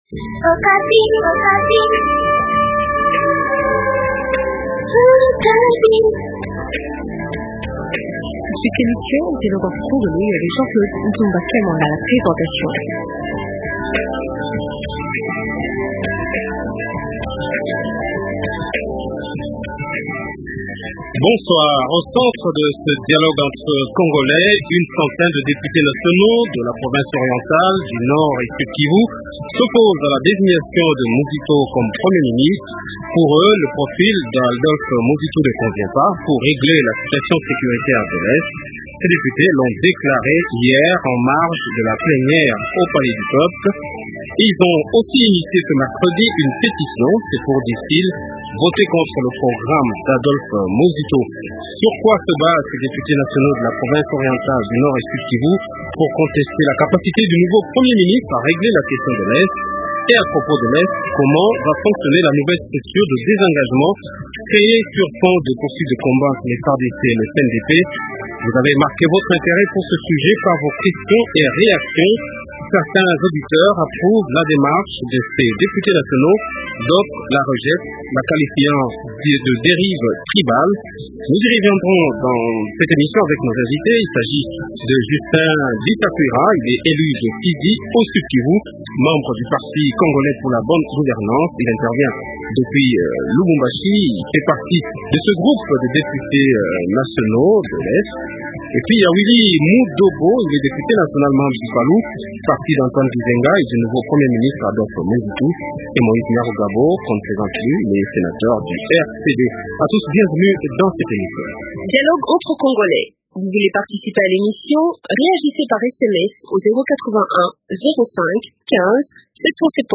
Moise Nyarugabo, sénateur du Rcdrn